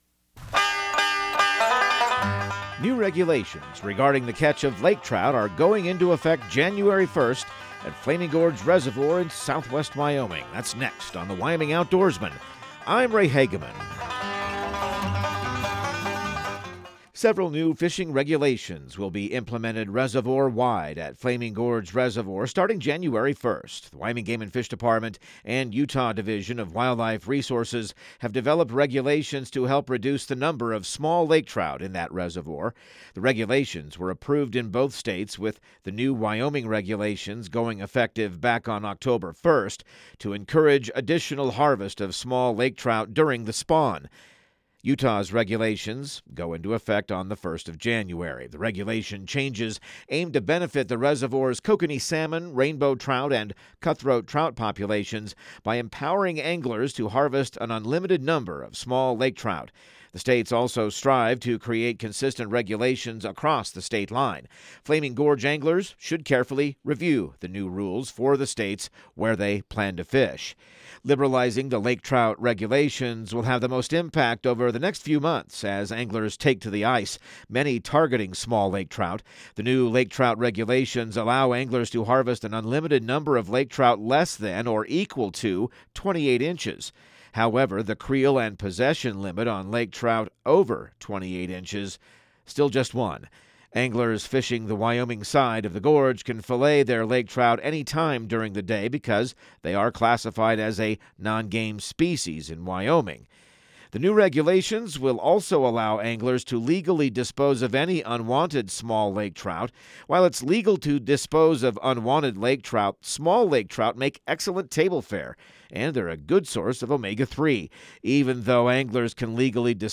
Radio news | Week of December 23